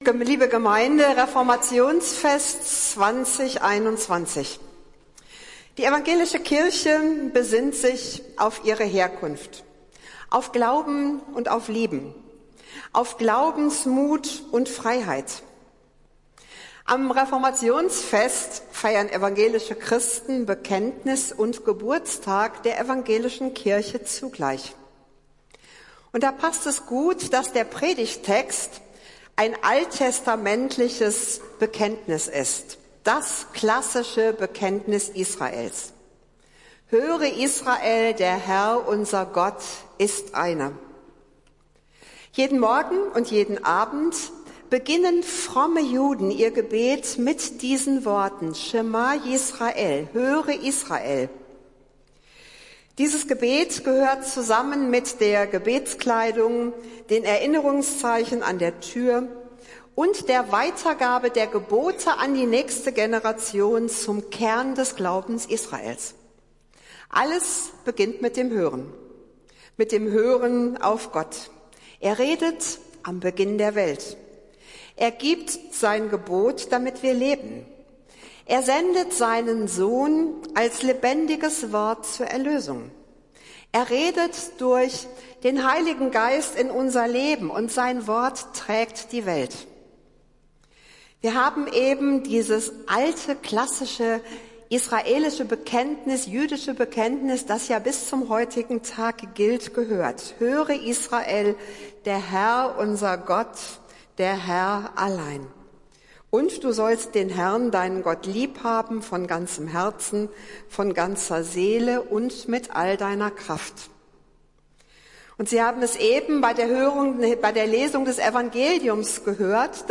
Predigt des Gottesdienstes aus der Zionskirche vom Sonntag, 31.10.2021